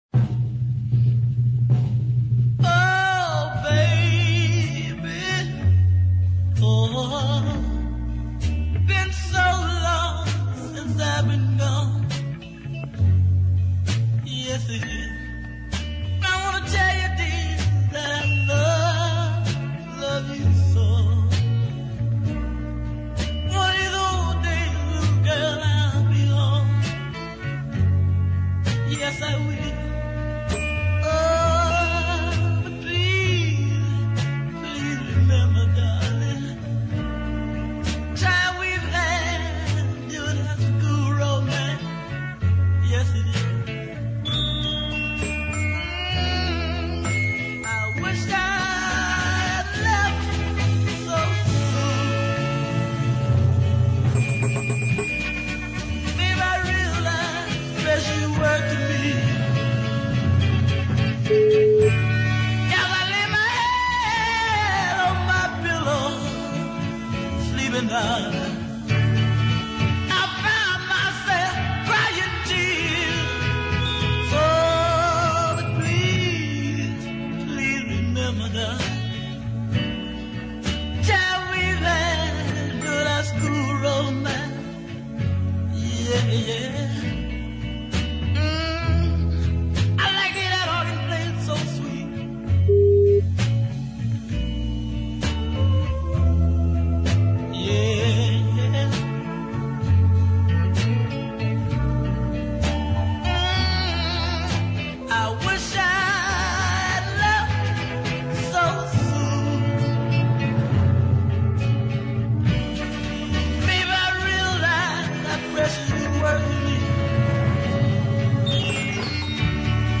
southern soul
deep ballad